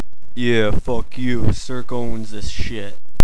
different profanity-filled sound files installed by rival hackers.